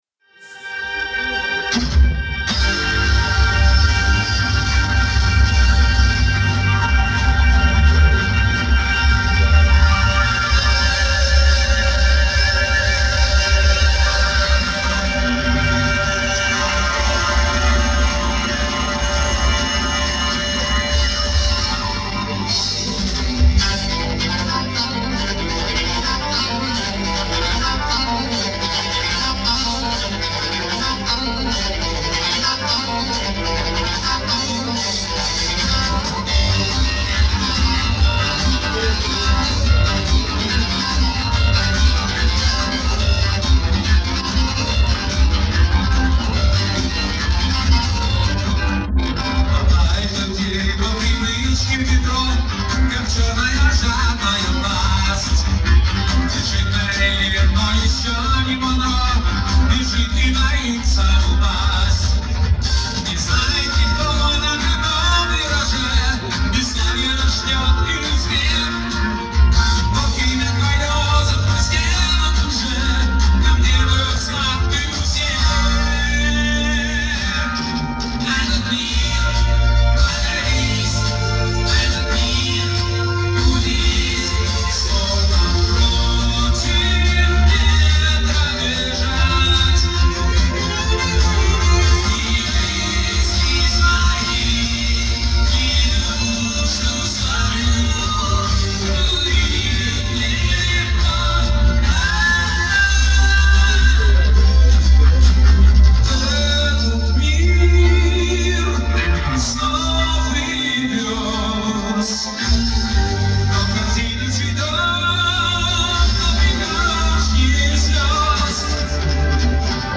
с зала микрофоном